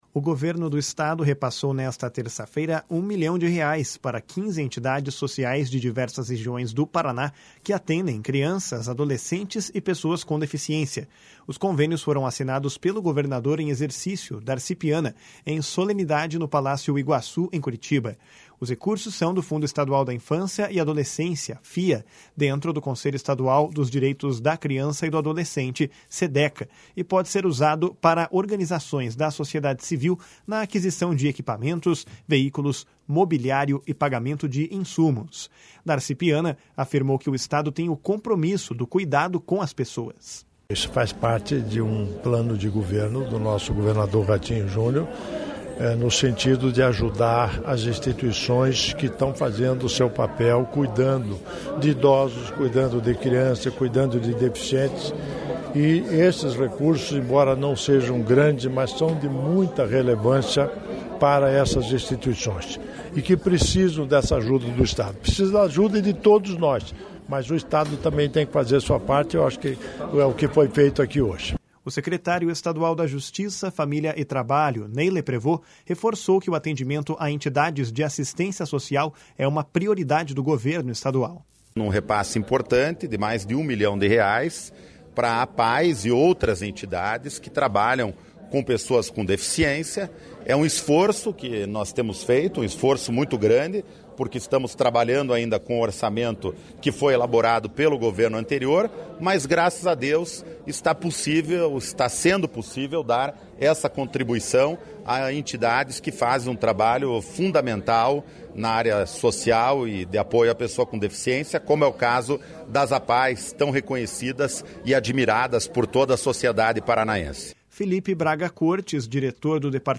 // SONORA DARCI PIANA //